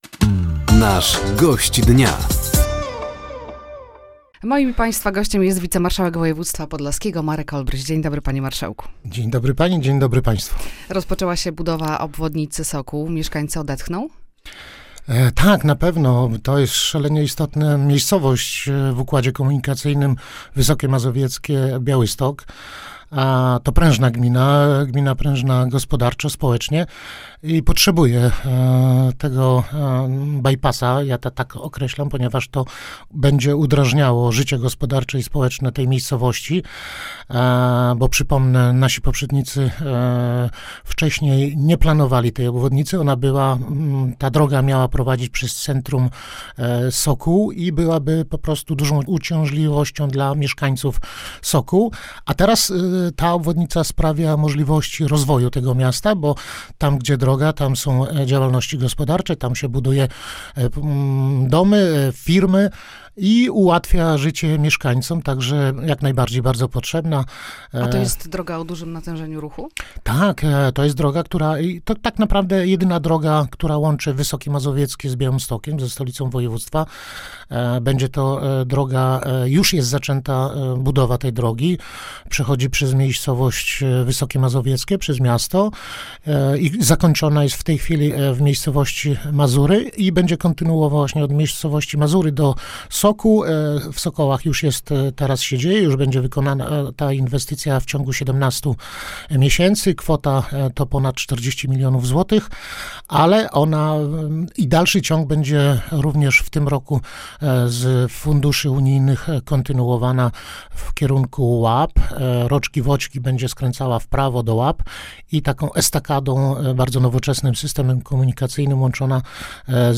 Gościem Dnia Radia Nadzieja był dzisiaj wicemarszałek województwa podlaskiego, Marek Olbryś. Tematem rozmowy była między innymi rozpoczęta budowa obwodnicy Sokół, wojewódzki program bezpieczeństwa ruchu drogowego i rozwój kolei.